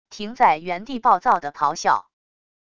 停在原地暴躁的咆哮wav音频